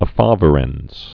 (ə-fävîr-ĕnz)